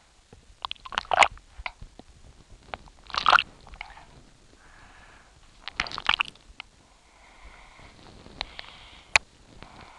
drinking.wav